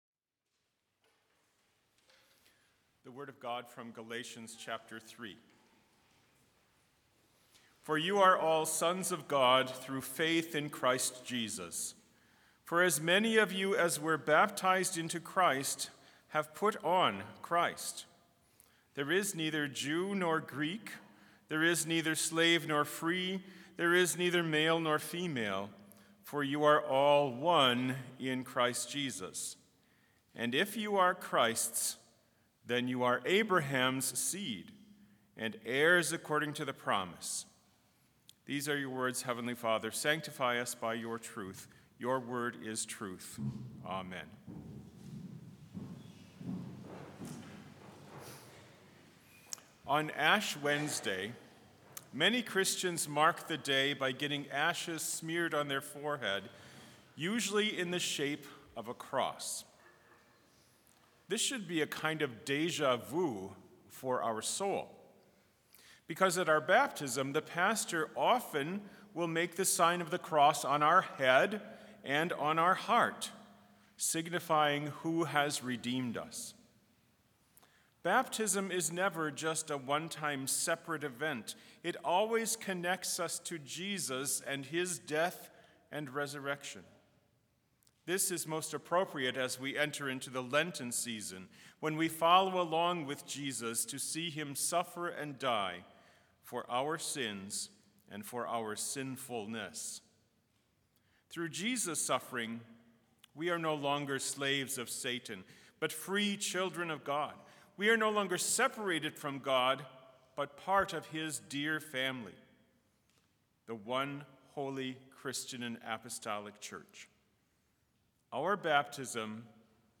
Complete Service
• Prelude
• Devotion
This Chapel Service was held in Trinity Chapel at Bethany Lutheran College on Friday, February 20, 2026, at 10 a.m. Page and hymn numbers are from the Evangelical Lutheran Hymnary.